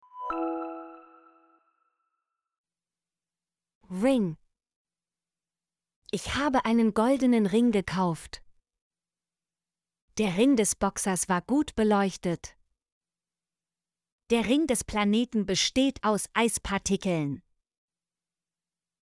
ring - Example Sentences & Pronunciation, German Frequency List